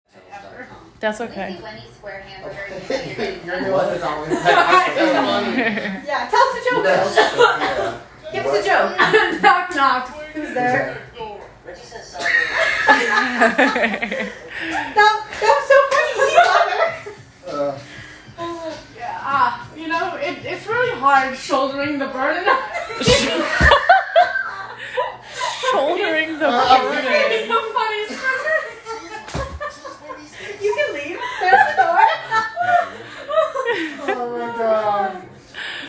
Field Recording #4
Location: Bill of Rights, Floor 10 lounge
Sounds heard: TV announcements, coughs, clapping, talking in unison and overlapping over each other, bodies shuffling.